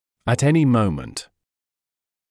[æt ‘enɪ ‘məumənt][эт ‘эни ‘моумэнт]в любую минуту; в любое время